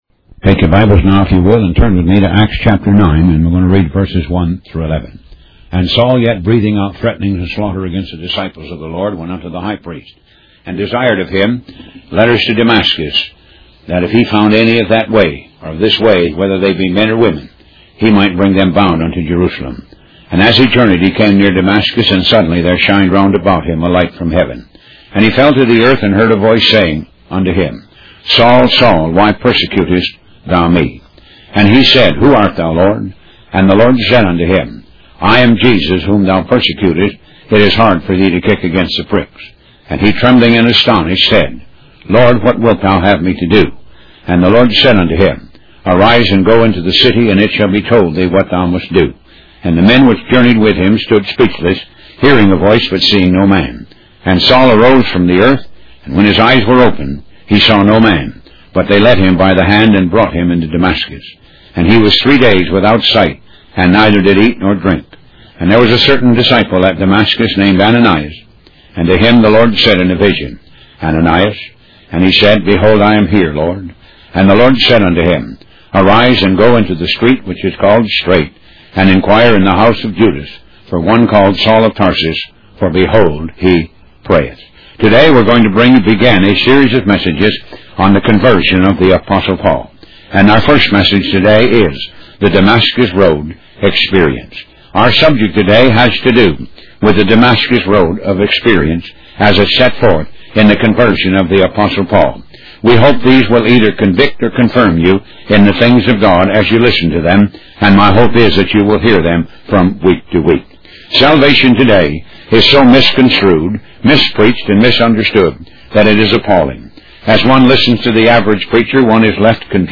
Talk Show Episode, Audio Podcast, Moga - Mercies of God Association and Road To Damascus Experience on , show guests , about Road To Damascus Experience, categorized as Health & Lifestyle,History,Love & Relationships,Philosophy,Psychology,Christianity,Inspirational,Motivational,Society and Culture